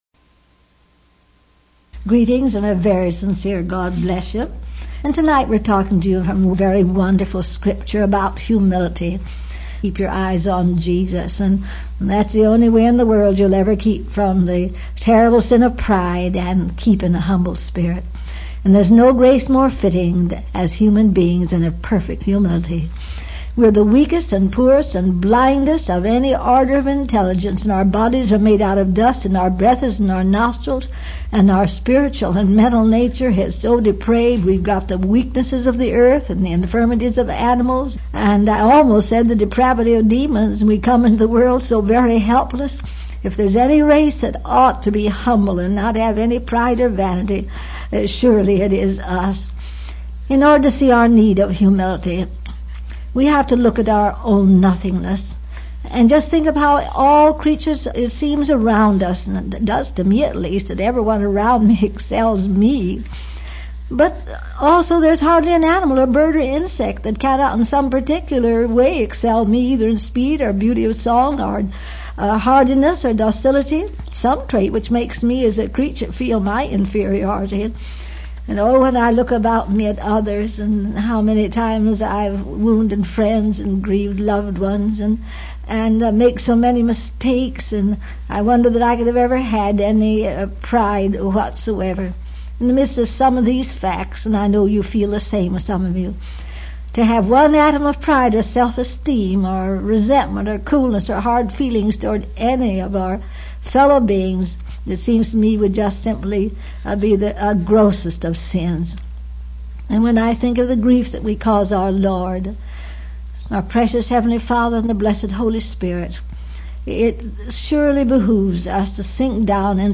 This was a transcript of a Meditation Moments #47 broadcast.